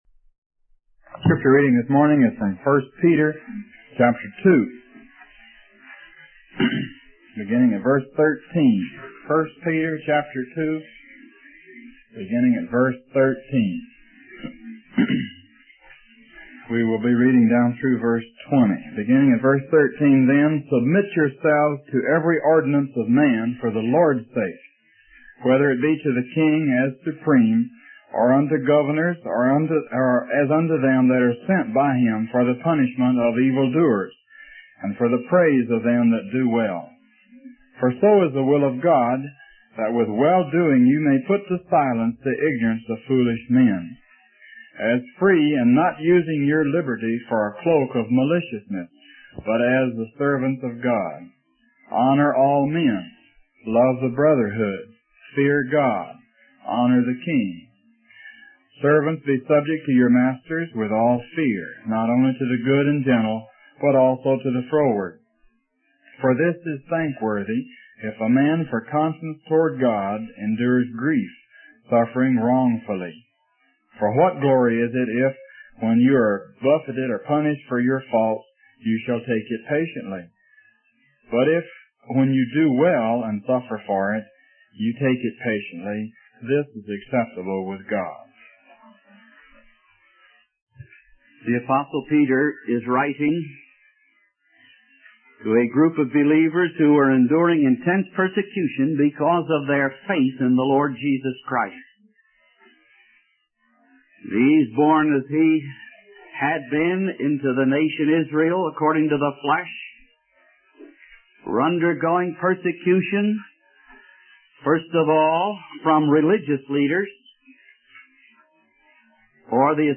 The preacher urges the audience to take the message of Christ's sacrifice to others and encourage them to submit to His authority. He also highlights the importance of being in submission to the government and using our freedom responsibly, as outlined in 1 Peter 2:13-20.